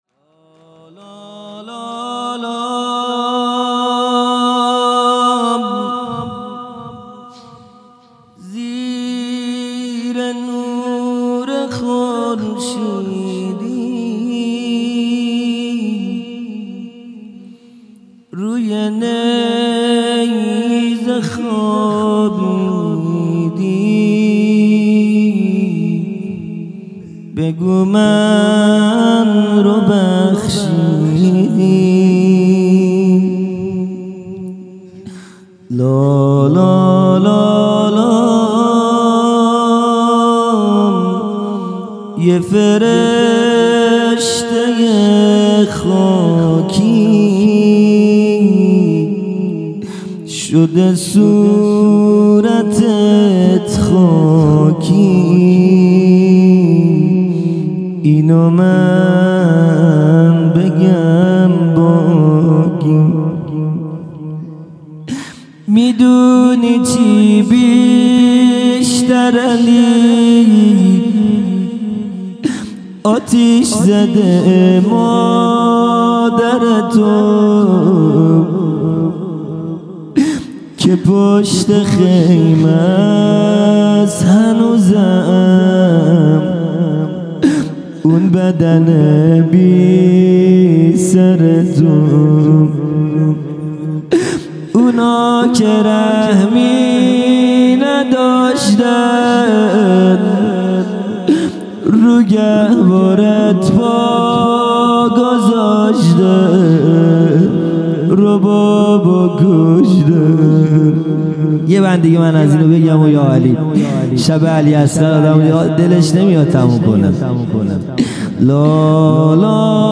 خیمه گاه - هیئت بچه های فاطمه (س) - مناجات پایانی | زیر نور خورشیدی
جلسۀ هفتگی